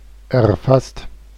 Ääntäminen
Vaihtoehtoiset kirjoitusmuodot (vanhentunut) erfaßt Ääntäminen Tuntematon aksentti: IPA: [ʔɛɐ̯ˈfast] Haettu sana löytyi näillä lähdekielillä: saksa Käännöksiä ei löytynyt valitulle kohdekielelle. Erfasst on sanan erfassen partisiipin perfekti.